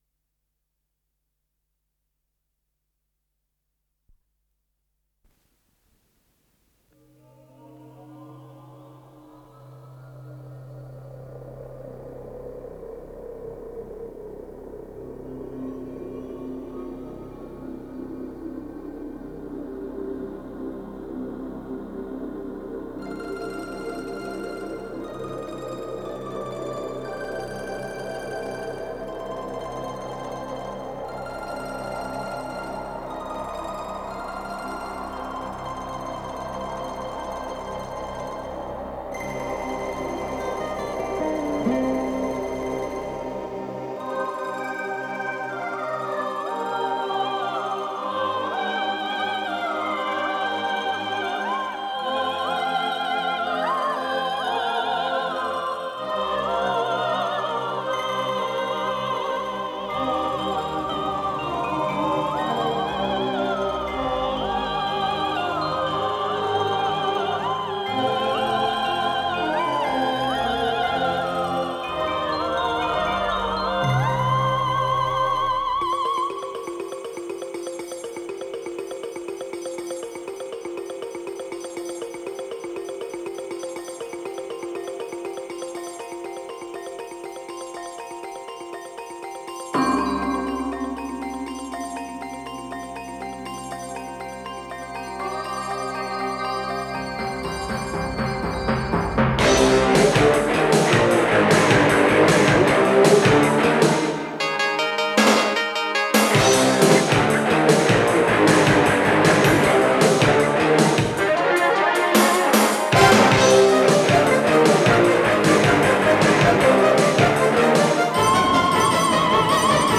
с профессиональной магнитной ленты
ПодзаголовокПьеса с вокализом
вокал
ВариантДубль моно